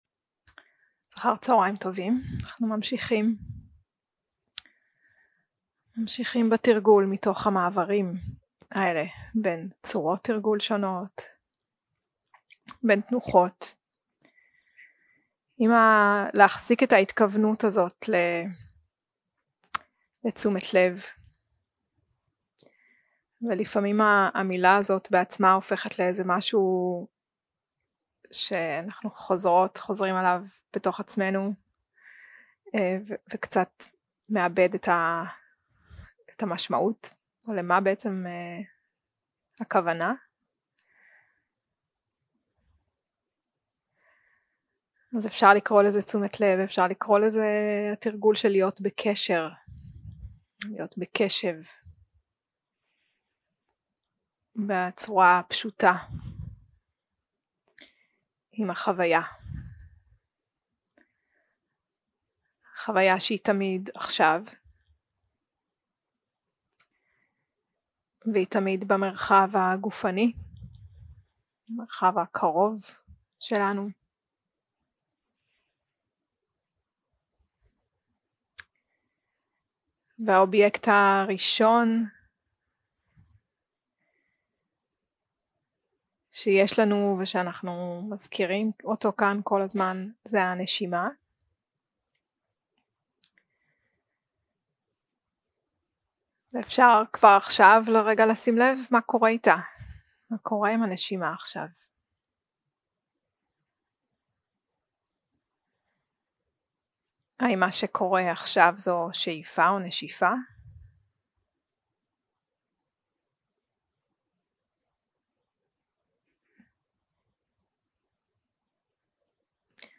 יום 3 – הקלטה 6 – צהריים – מדיטציה מונחית – ודאנה וגוף
Dharma type: Guided meditation שפת ההקלטה